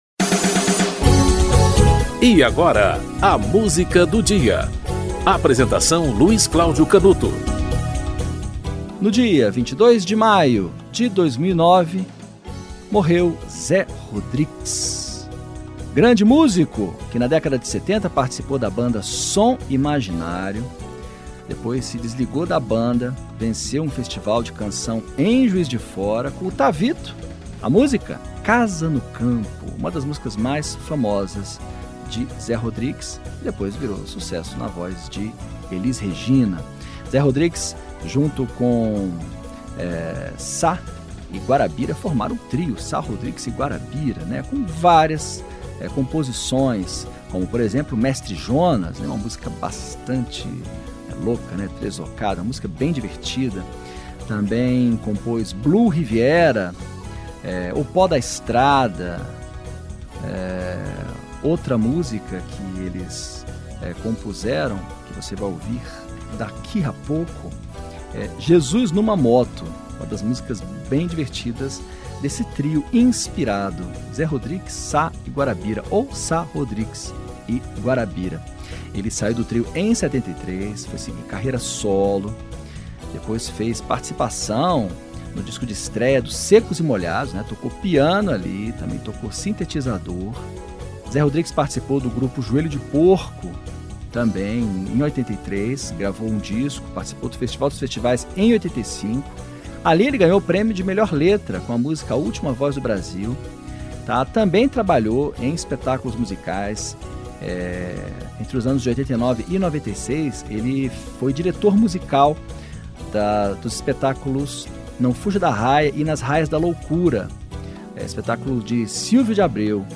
Zé Rodrix - Jesus Numa Moto (Sá, Rodrix e Guarabyra)
Produção e apresentação